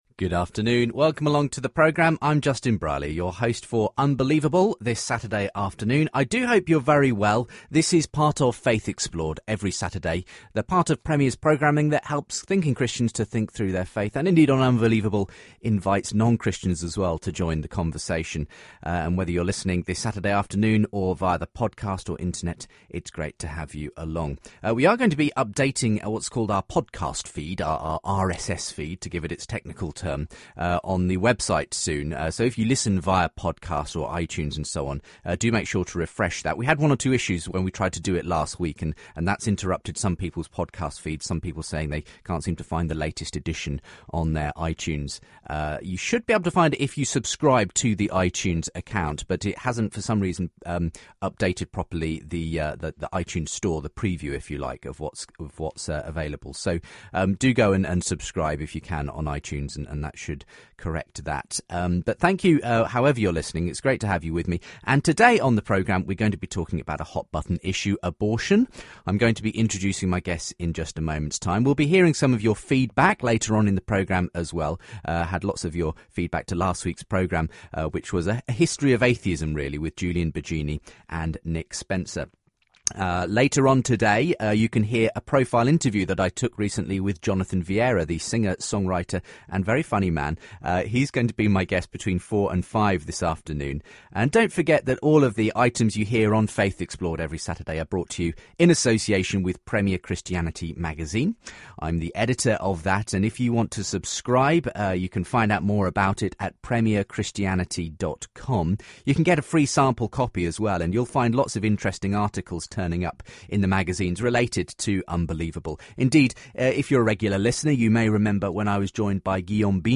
Christianity, Religion & Spirituality